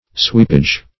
\Sweep"age\
sweepage.mp3